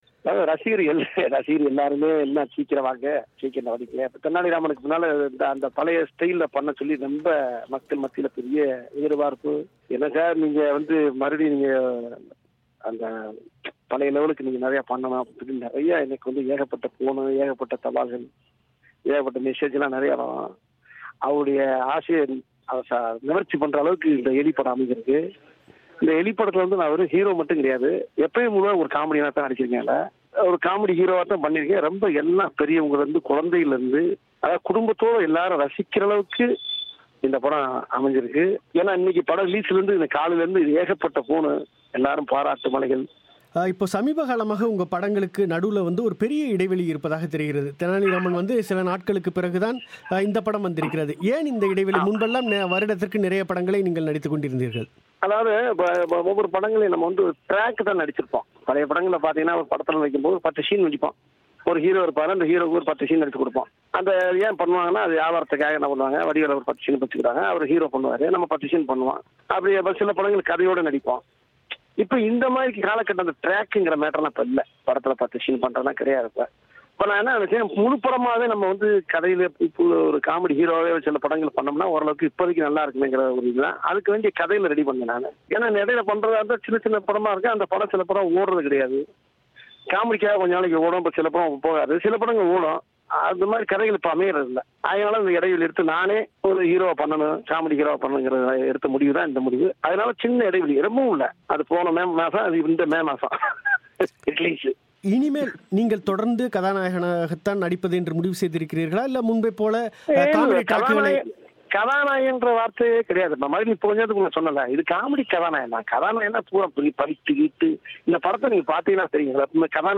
கணிசமான கால இடைவெளிக்குப் பிறகு நடிகர் வடிவேலு நடித்த எலி திரைப்படம் வெளிவந்துள்ள நிலையில் பிபிசி தமிழோசையிடம் பேசிய நடிகர் வடிவேலு, இனிமேல் சிறிய வேடங்களில் நடிக்கப்போவதில்லை என்றும், கதாநாயகனாக மட்டுமே நடிக்கப் போவதாகவும் தெரிவித்தார். தன்னிடம் பத்துக்கும் மேற்பட்ட திரைப்படக் கதைகள் கைவசம் இருப்பதாகவும், இனிமேல் அரசியல் பக்கம் செல்லப்போவதில்லை என்றும் கூறினார்.